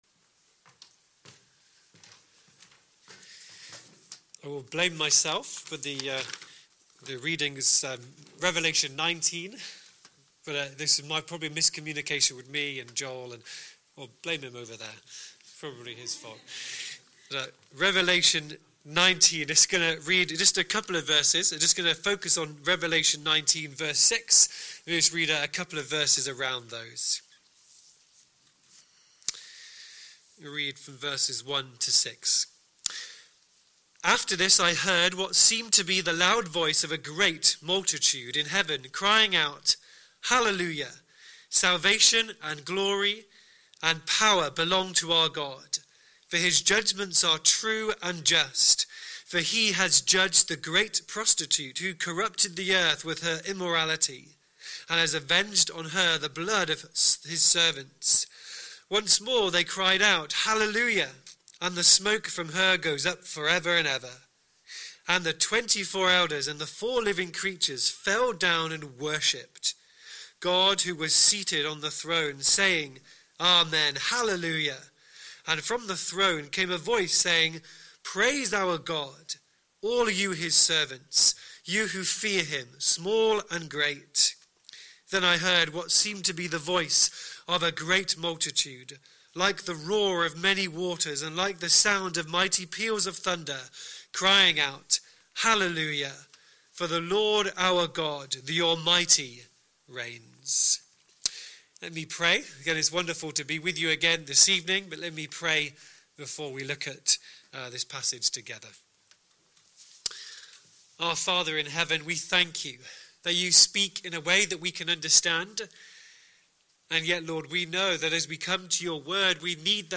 Sunday Morning Service Speaker